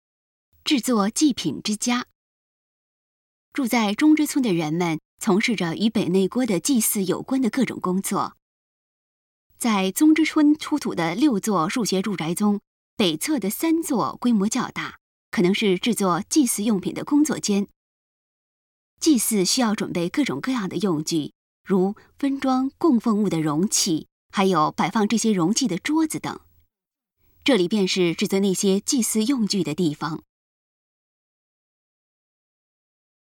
语音导览 前一页 下一页 返回手机导游首页 (C)YOSHINOGARI HISTORICAL PARK